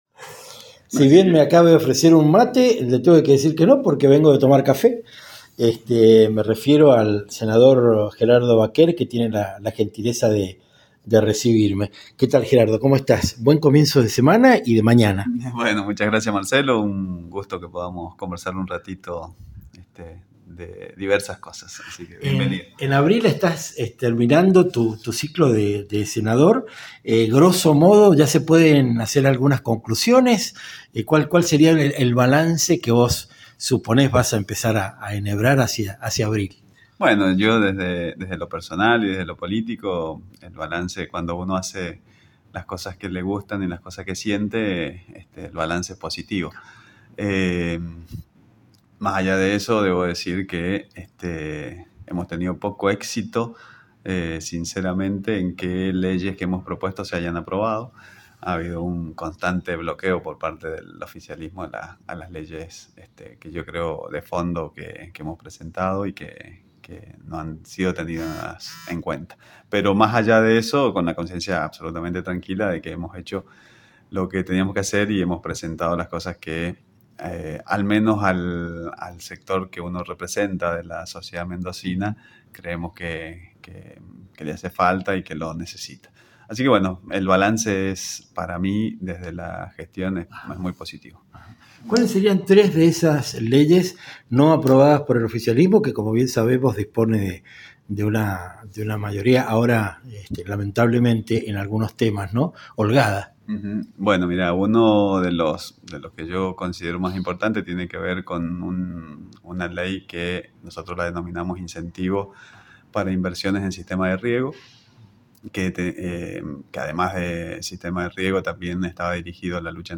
Entrevistas militantes: Gerardo Vaquer, porque el agua pura se defiende -